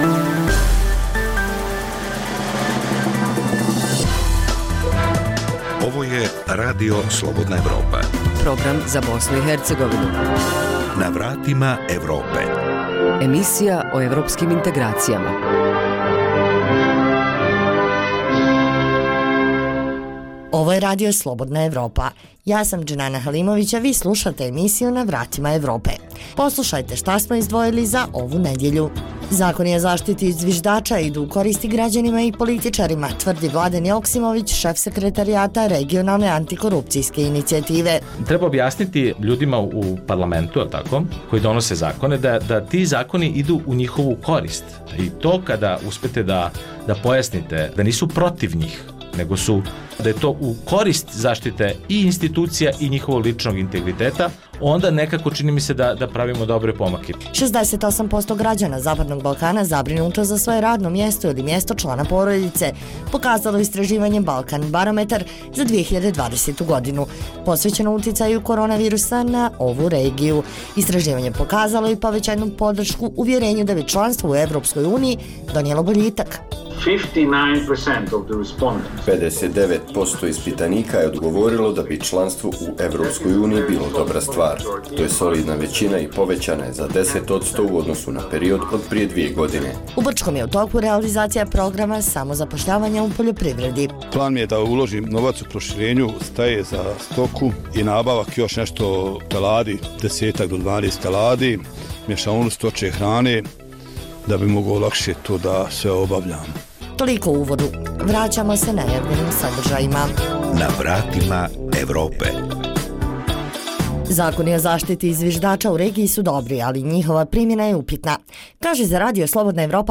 vijesti, analize, reportaže